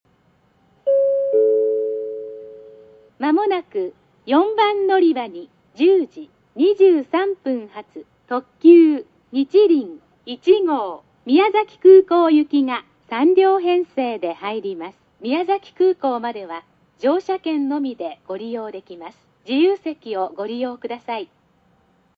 駅の音
スピーカー：ソノコラム
音質：B
接近前放送（特急にちりん１号）　(108KB/22秒)
音割れ等無く、九州内では綺麗な部類の音質です。